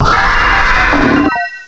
cry_not_palkia.aif